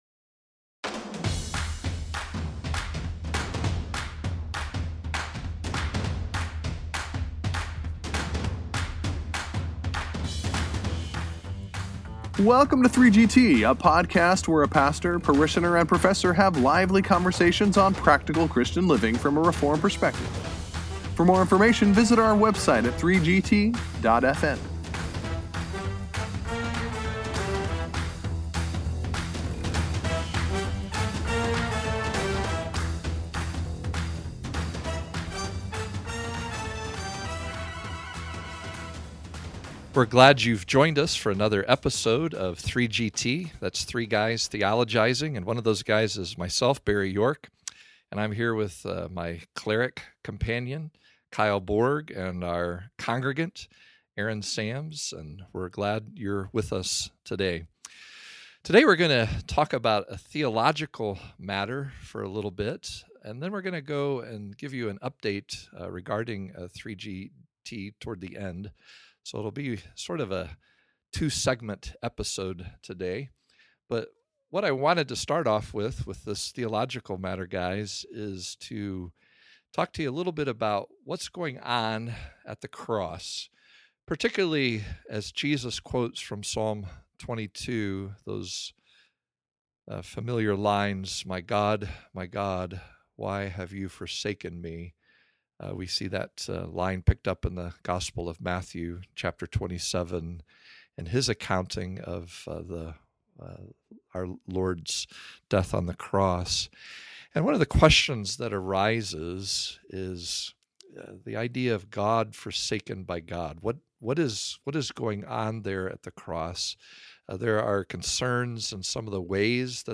Then, in the second part of this episode, the guys discuss the idea of forsaken in whole other way. For one of the 3GTers is leaving!